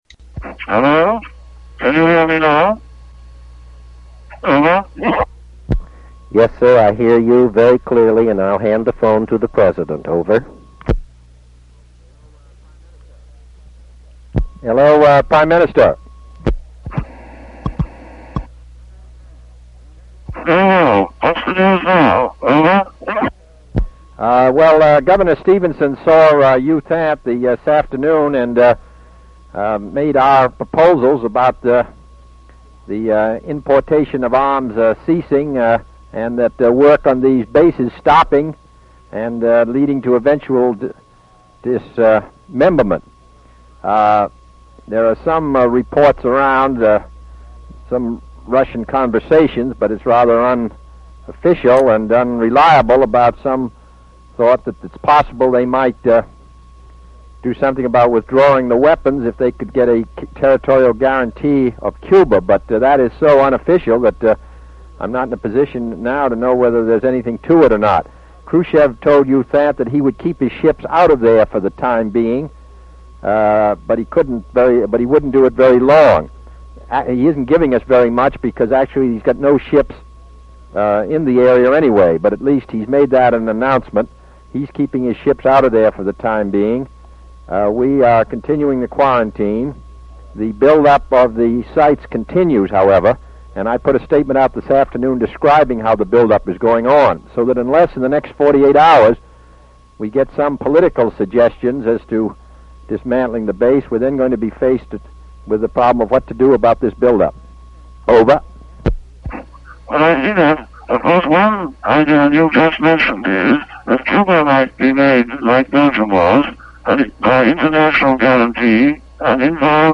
Tags: White House tapes Presidents Secret recordings Nixon tapes White house